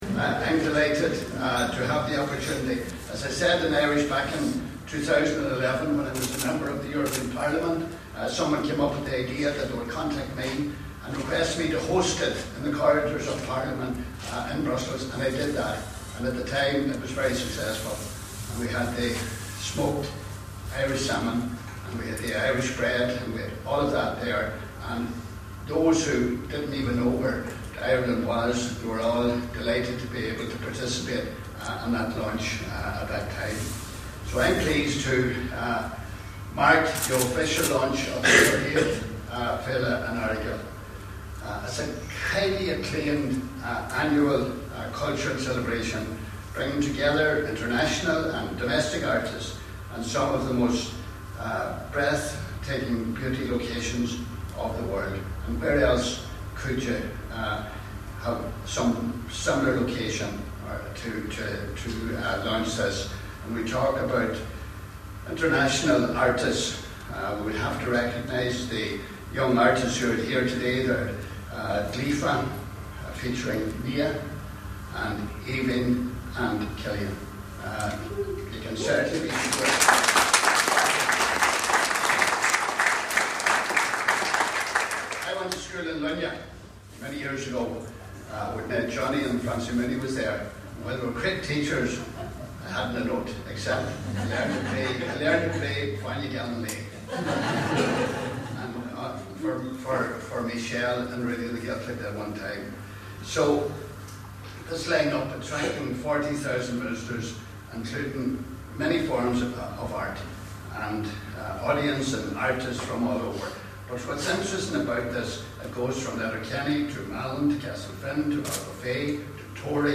The launch took place in Gaoth Dobhair yesterday and was performed by Deputy Pat the Cope Gallagher.
Speaking yesterday, Deputy Gallagher said Earagail Arts Festival is a cultural celebration, that brings artists from around the world together….